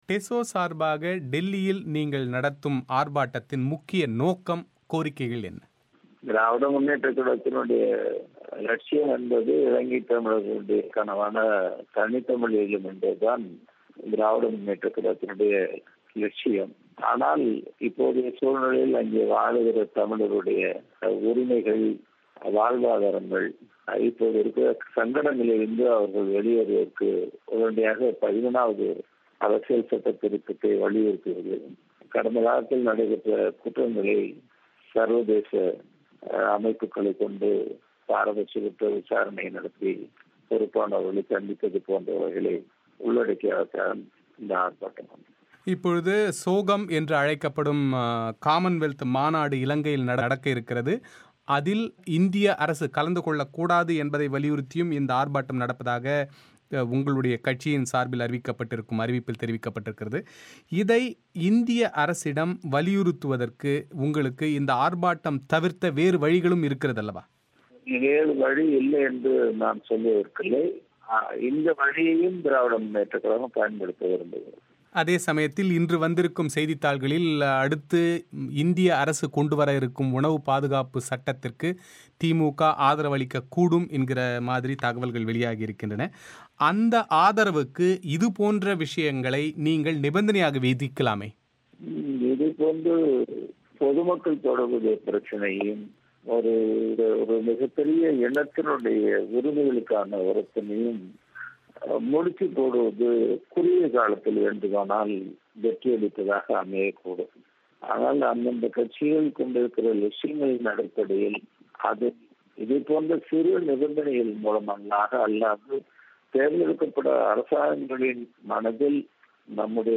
புது தில்லியில் திமுக நடத்தும் இந்த ஆர்பாட்டம் அரசியல் நோக்கங்களுக்கானதா என்கிற கேள்விக்கு பதிலளிக்கிறார் திமுகவைச் சேர்ந்த முன்னாள் மத்திய அமைச்சர் பழனி மாணிக்கம்.